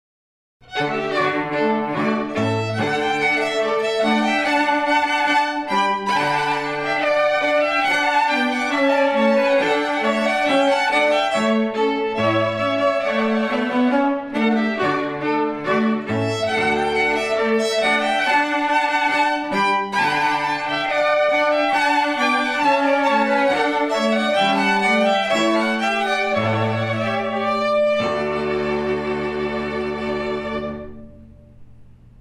Rondeau Mouret String Quartet